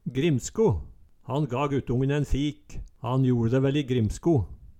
grimsko - Numedalsmål (en-US)